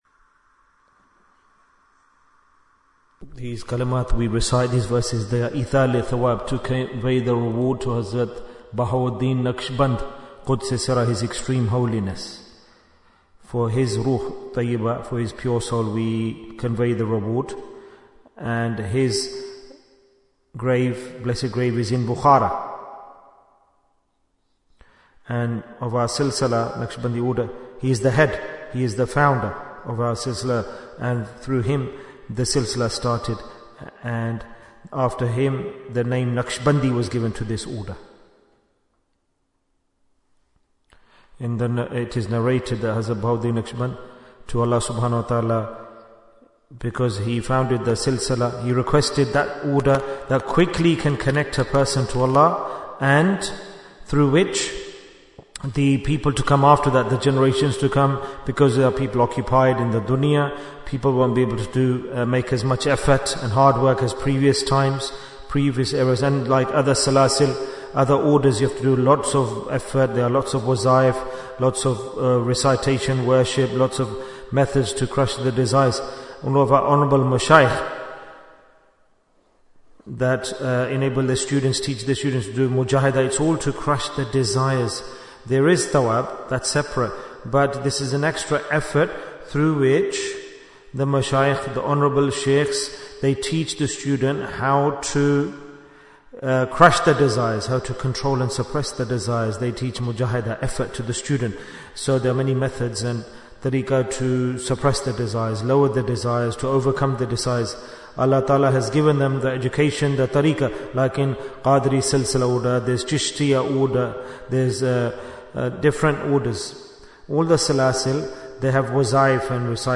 Jewels of Ramadhan 2025 - Episode 24 Bayan, 17 minutes19th March, 2025